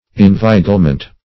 Inveiglement \In*vei"gle*ment\, n.